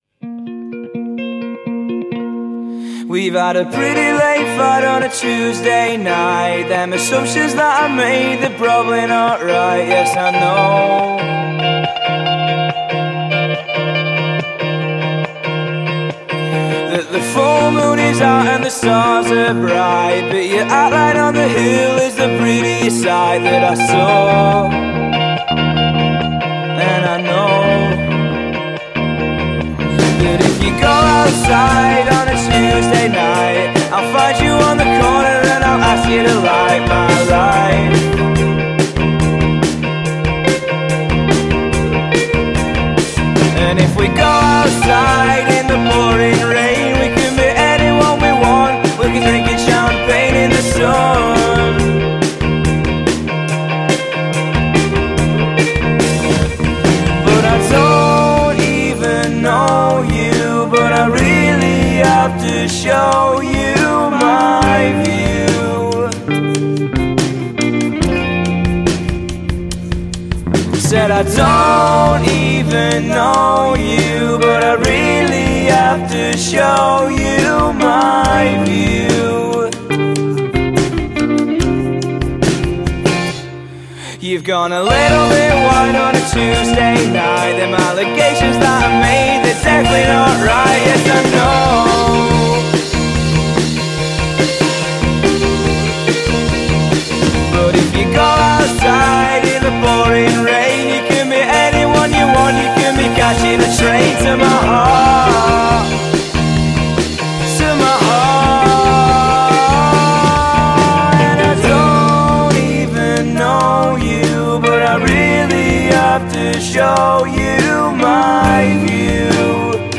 indie band